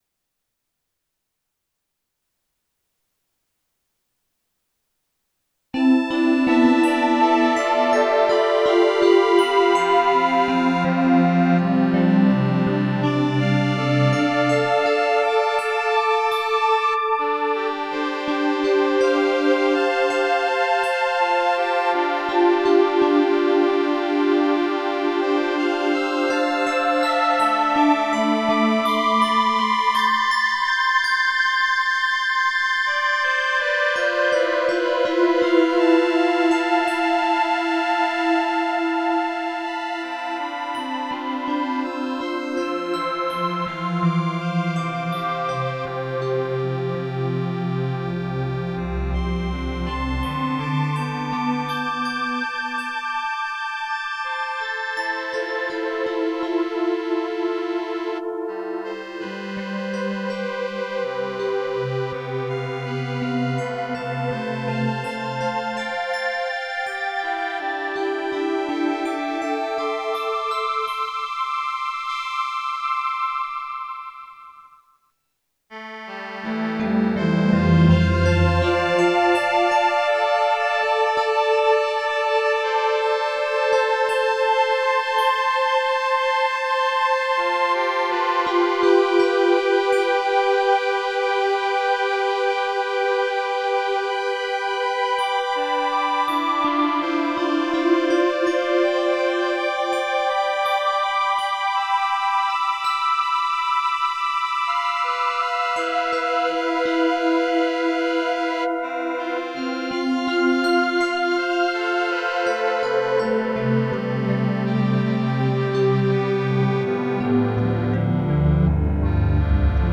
Lépicia enregistrée dans notre jardin botanique au couvet des fleurs à Saint Maximin le 27 octobre 2024 à partir de 15h.15
L’épicéa en symphonie à 4 expressions musicales mélangées :
pad1 + reel organ + flute + voice oohs